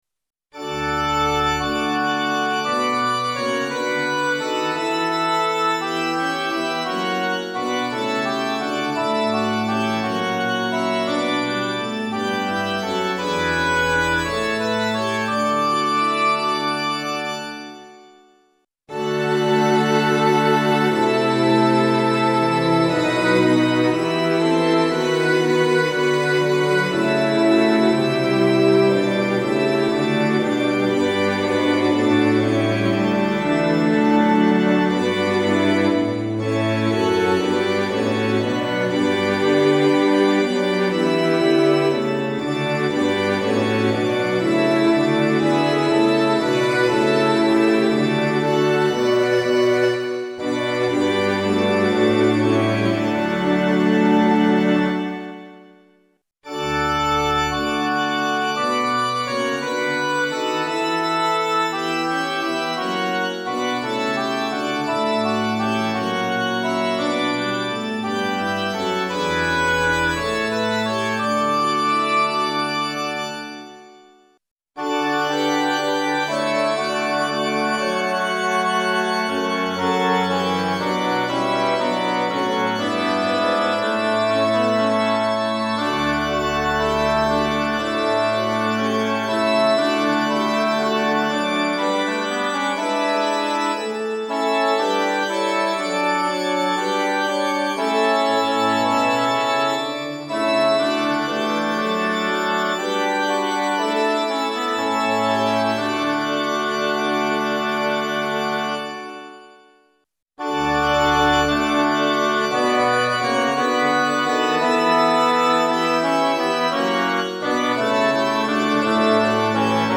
混声四部合唱+器楽 Four-part mixed chorus with Instruments
0.9.8.2 D Choir(S,A,T,B)